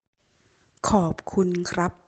Pour vous entraîner voici la bonne prononciation:
audio-merci-en-thai-homme.mp3